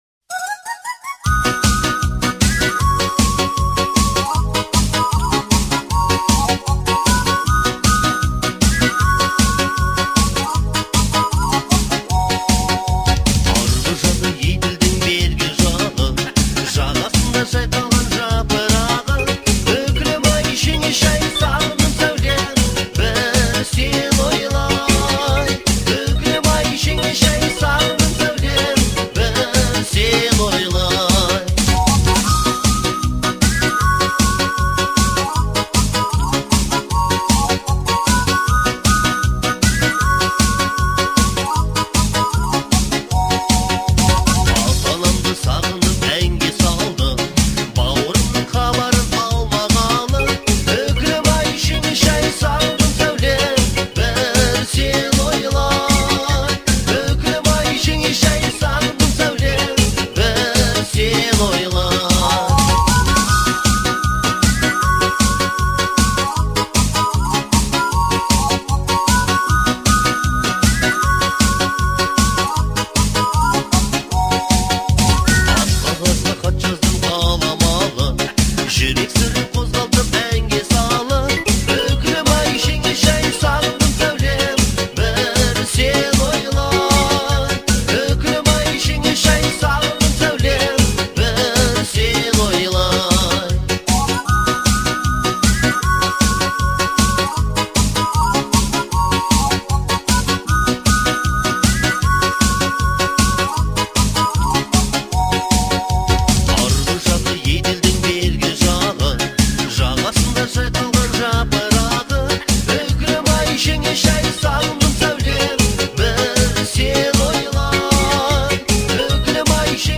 Жанр — народная музыка с элементами фольклора.